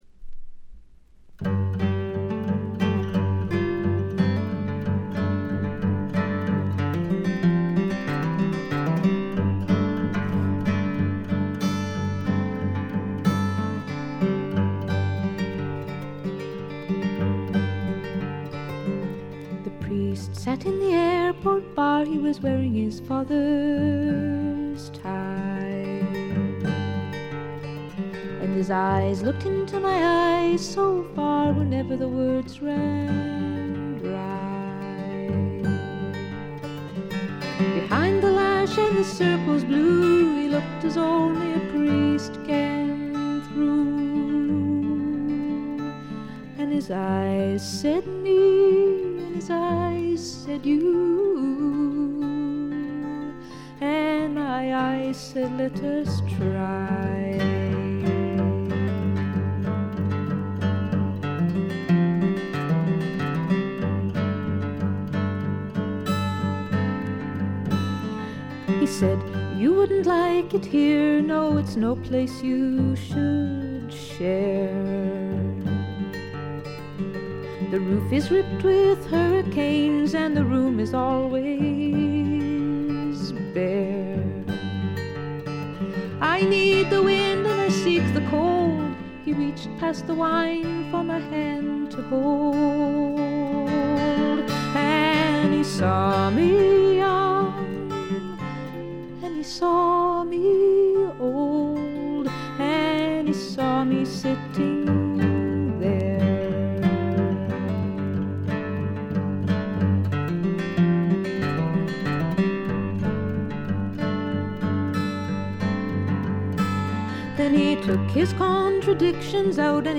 ところどころで軽微なチリプチ。
美しいことこの上ない女性シンガー・ソングライター名作。
試聴曲は現品からの取り込み音源です。